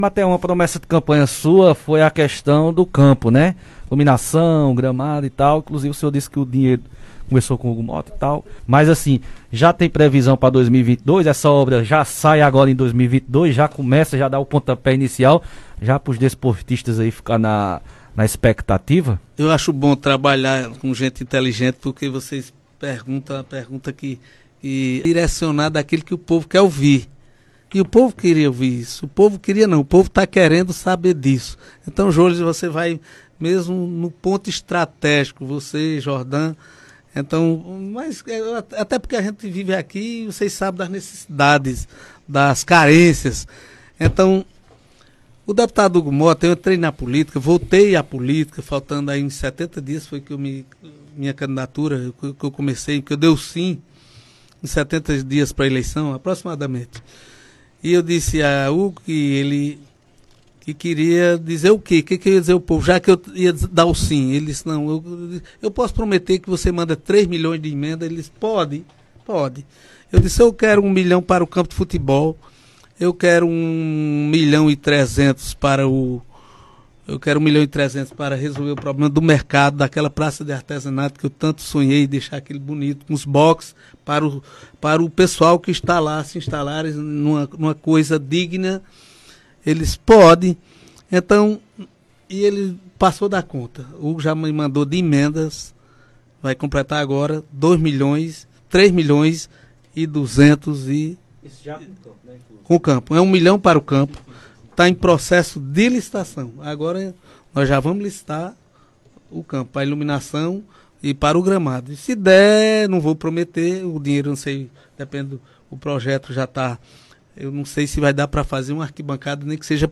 O prefeito de Santa Terezinha (PB), Arimateia Camboim (Republicanos), foi entrevistado na sexta-feira (24), no Conexão com a Notícia, transmitido pela Rádio Conexão FM de Santa Terezinha.